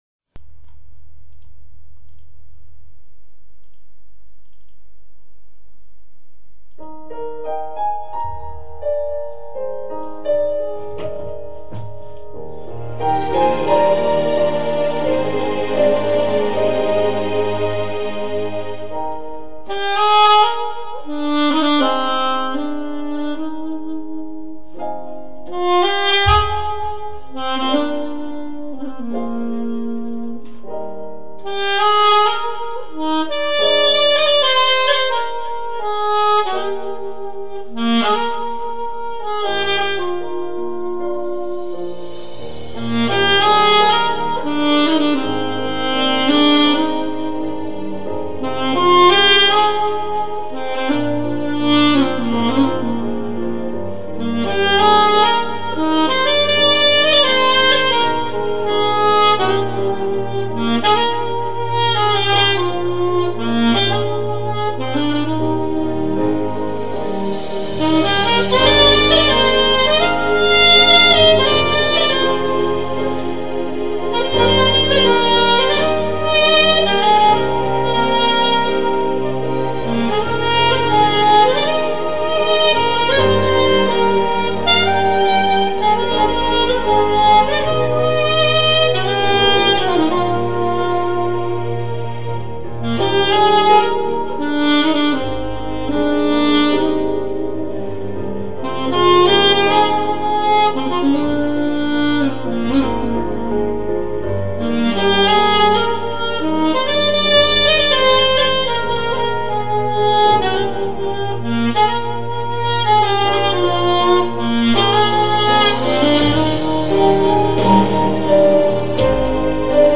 高音吹着很费劲